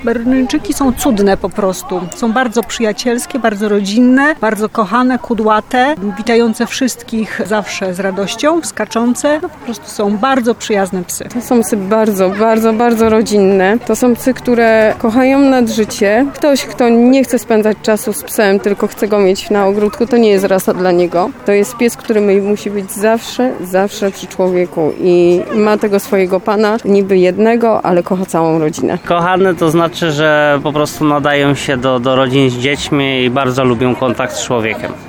Pochód składał się z niemal setki Berneńczyków. Było głośno, kolorowo i radośnie.
Dzisiaj (20.06) pieski zaprezentowały się mieszkańcom w uroczystej paradzie, która przeszła ulicami miasta.